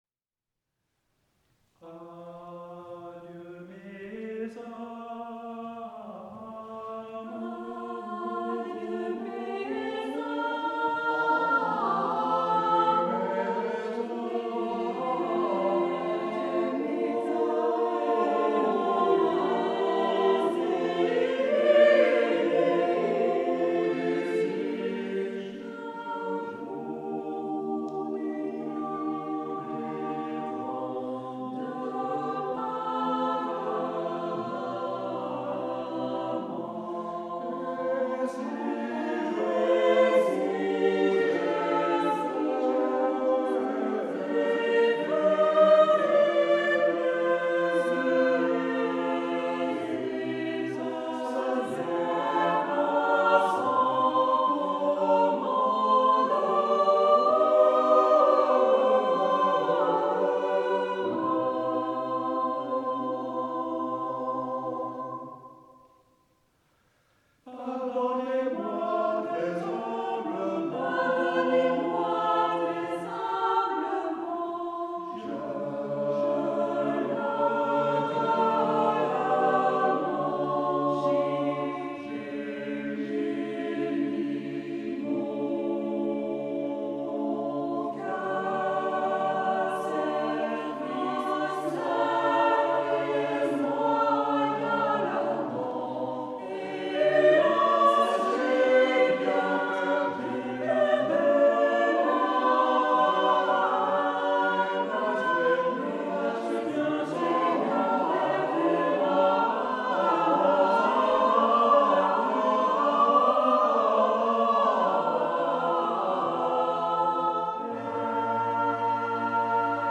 - Le mardi 31 mai 2011 à 20h00 au temple de Nyon, VD, Suisse.
Quelques extraits de Nyon: